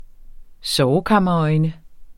Udtale [ -ˌʌjnə ]